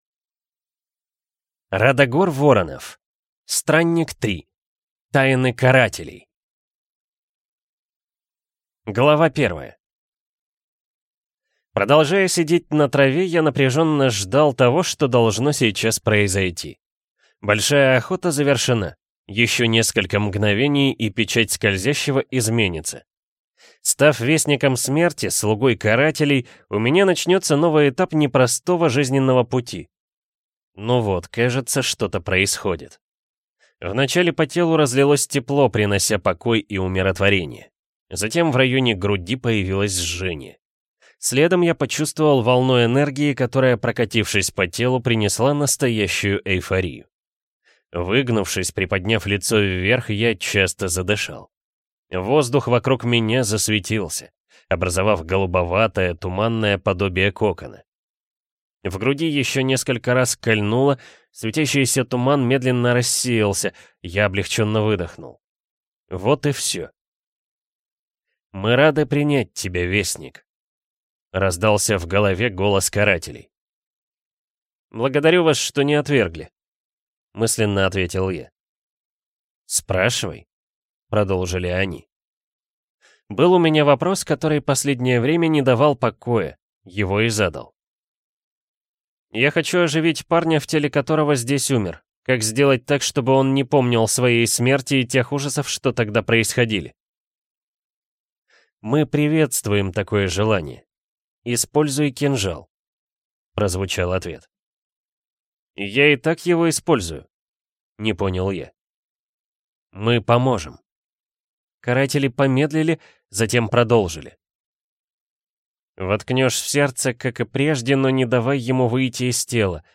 Аудиокнига Странник-3. Тайны Карателей | Библиотека аудиокниг